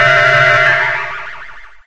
Confuse.ogg